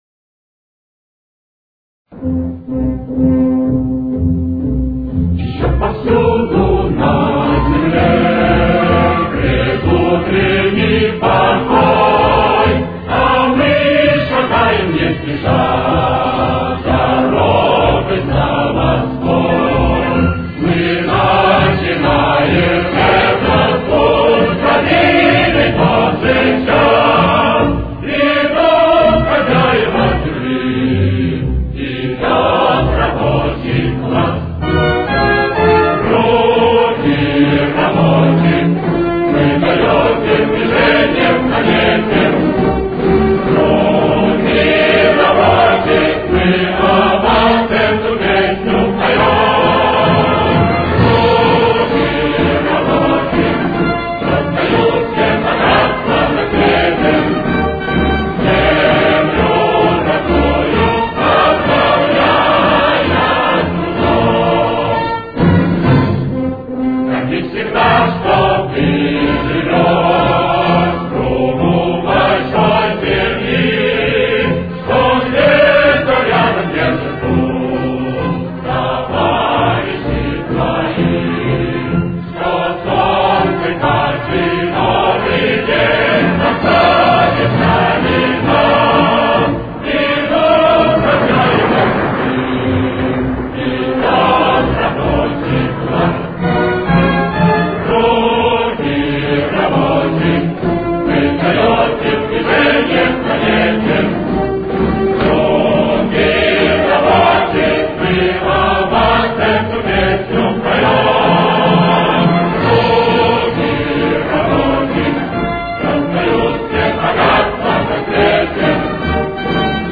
Темп: 132.